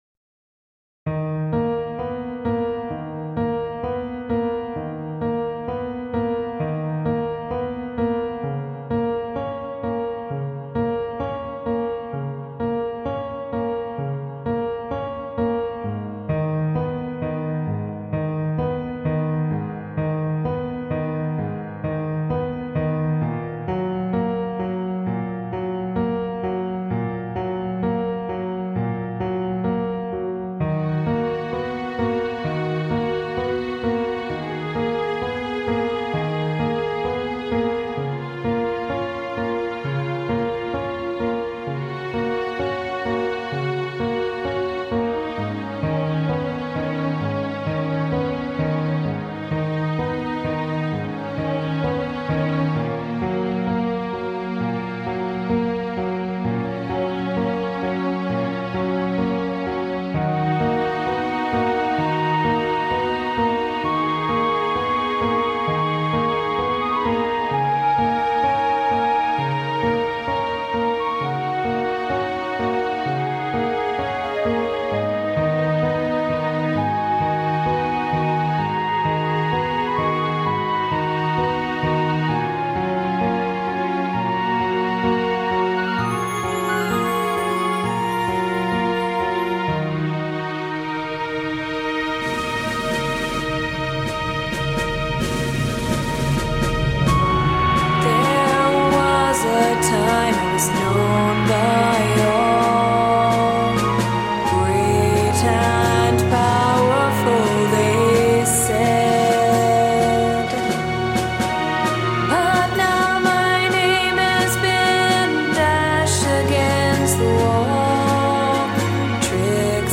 Right from the start I knew it had to be a sad trixie song.